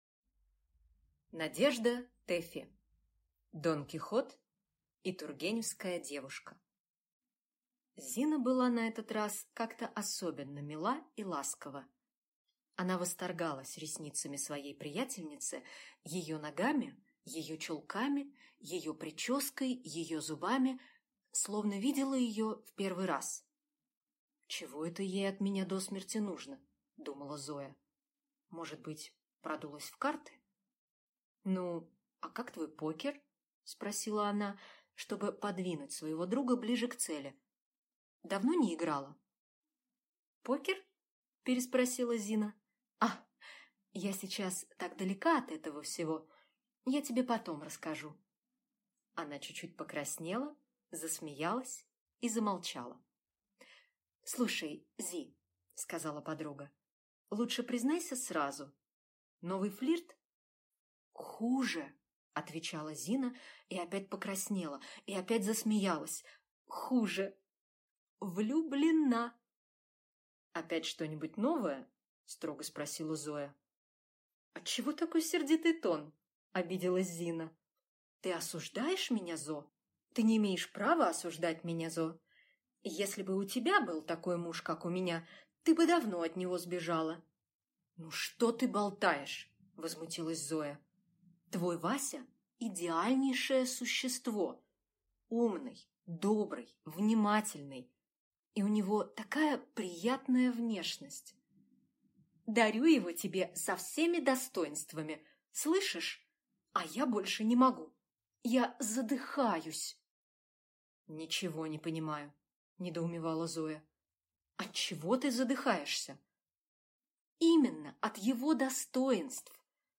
Аудиокнига Дон Кихот и тургеневская девушка | Библиотека аудиокниг